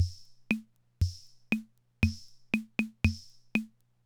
Index of /90_sSampleCDs/300 Drum Machines/Conn Min-O-Matic Rhythm/Conn Min-O-Matic Rhythm Ableton Project/Samples/Imported